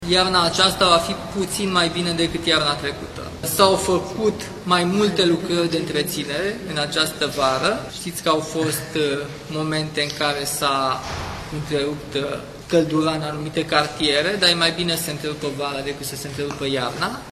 Întrebat de jurnalişti referitor la proiectul de lege promulgat de şeful statului ce prevede că întreruperile de apă caldă şi căldură în sezonul rece sunt interzise, el a răspuns că diferenţa este mare “între un text pe o hârtie şi o conductă din teren“.